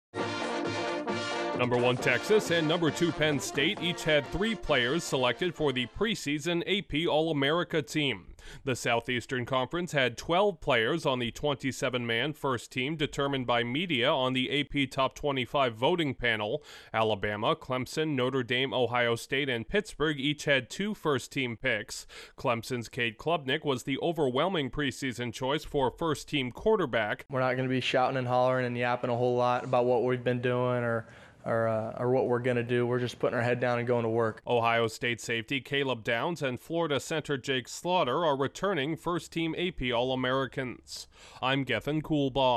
The top two college football teams in the nation are leading the charge on this year’s AP preseason All-America team. Correspondent